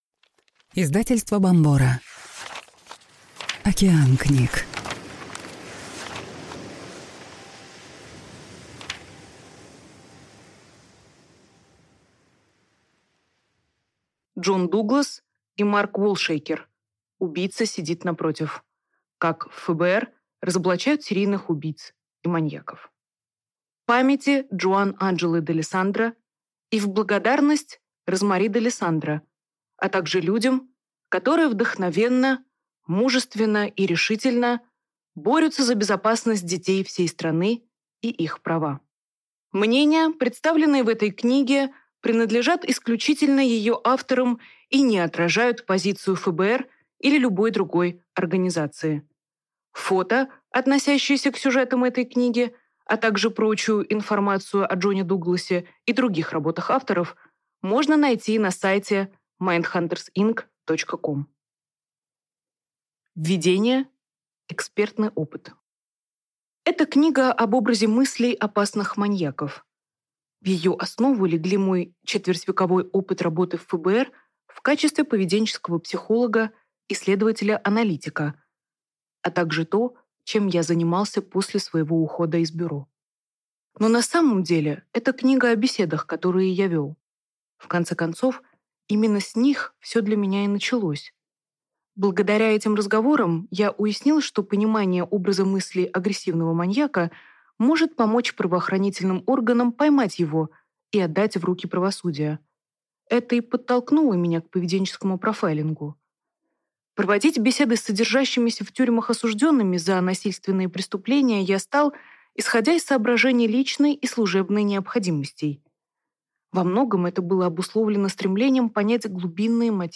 Аудиокнига Убийца сидит напротив. Как в ФБР разоблачают серийных убийц и маньяков | Библиотека аудиокниг